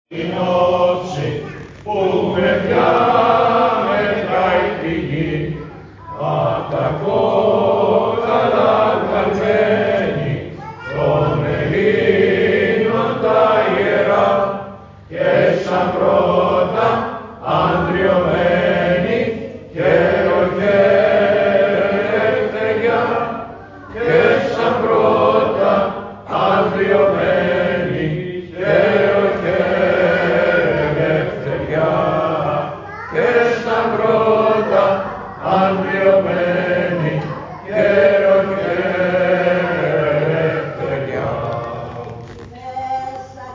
Με μεγάλη συμμετοχή η διπλή εορτή της Εθνικής μας Επετείου και του Ευαγγελισμού της Θεοτόκου (ηχητικό)
Στην Πανηγυρική Θεία Λειτουργία της διπλής εορτής του Ευαγγελισμού της Θεοτόκου και της επετείου του ξεσηκωμού των Ελλήνων εναντίον της μεγάλης τότε Οθωμανικής αυτοκρατορίας, συμμετείχε το εκκλησίασμα παρακολουθώντας με κατάνυξη την Θεία Λειτουργία και ψάλωντας μαζί με τον ιερέα το “Τη Υπερμάχω Στρατηγώ” και τον